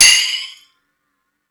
Space Drums(37).wav